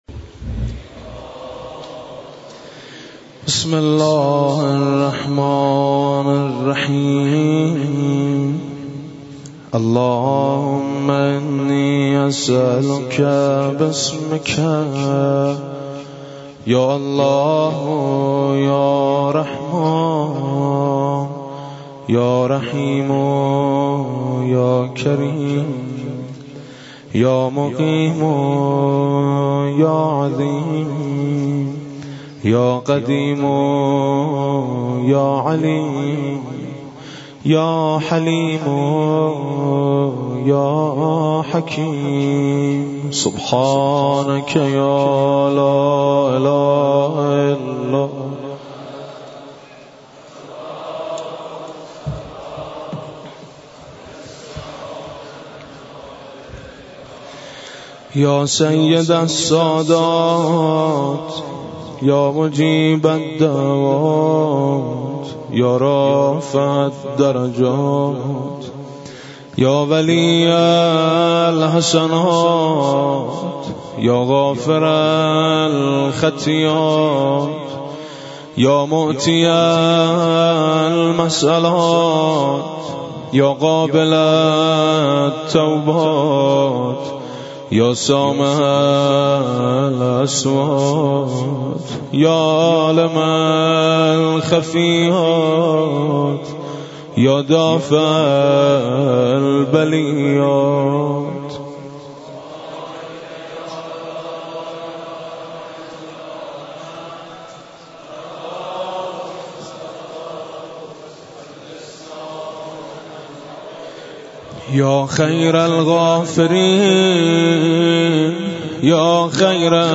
مراسم شب نوزدهم ماه مبارک رمضان با مداحی حاج میثم مطیعی در مسجد جامع بازار تهران برگزار گردید.
کد خبر : ۵۸۱۰۱ عقیق:صوت این جلسه را بشنوید دعای جوشن کبیر | شب 19 رمضان سال 1394 – مسجد جامع بازار تهران لینک کپی شد گزارش خطا پسندها 0 اشتراک گذاری فیسبوک سروش واتس‌اپ لینکدین توییتر تلگرام اشتراک گذاری فیسبوک سروش واتس‌اپ لینکدین توییتر تلگرام